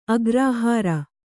♪ agrāhāra